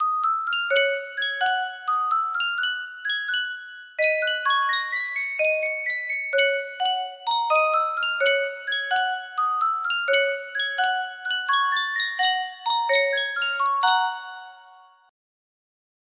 Jokiwa Music Box - Scott Joplin The Entertainer S9 music box melody
Full range 60